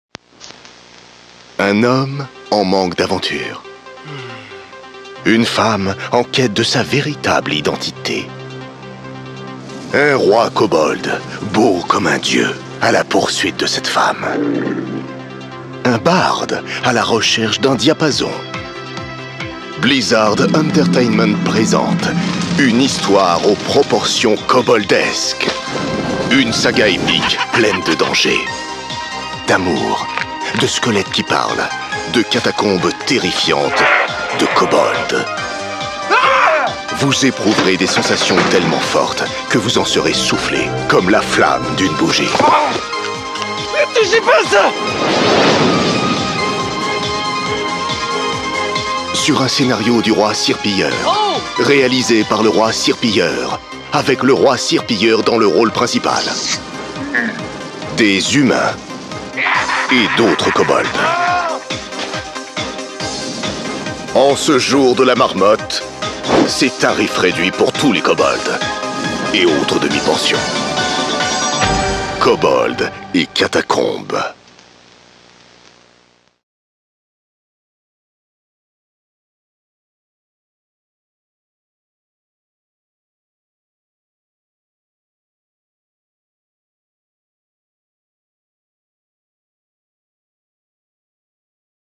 Ma prestation voix-off décalée et drôle pour Hearthstone : À la poursuite de la bougie perdue
Parodique. Voix style 80'.
Enregistré chez La Marque Rose.
Le ton devait être à la fois ridicule, ringard et drôle, une véritable parodie des voix des années 80.
Mon timbre de voix grave a apporté la profondeur nécessaire pour accentuer l’effet parodique et rendre la publicité encore plus drôle. L’enregistrement s’est déroulé chez La Marque Rose, un studio réputé pour son expertise et son professionnalisme.